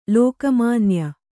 ♪ lōka mānya